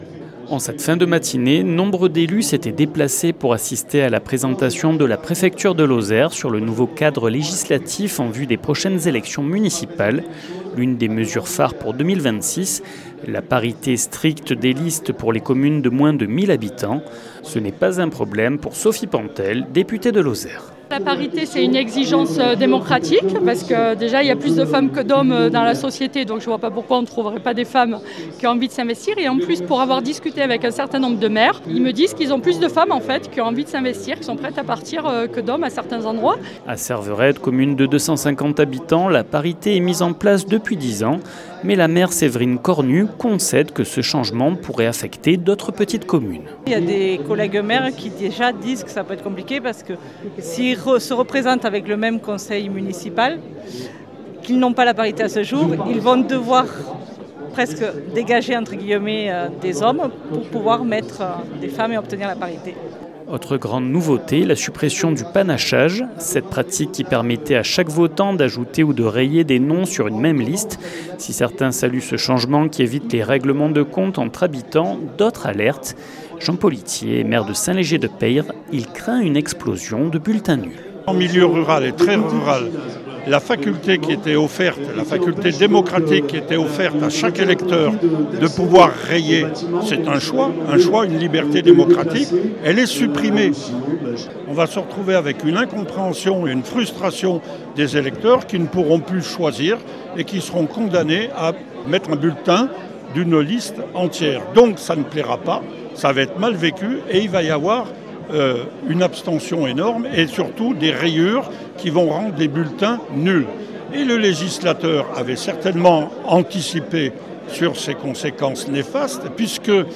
Un moment d’échange qui a pu soulever de la crainte dans le public. 48FM était sur place et a recueilli l’avis des élus.
Reportage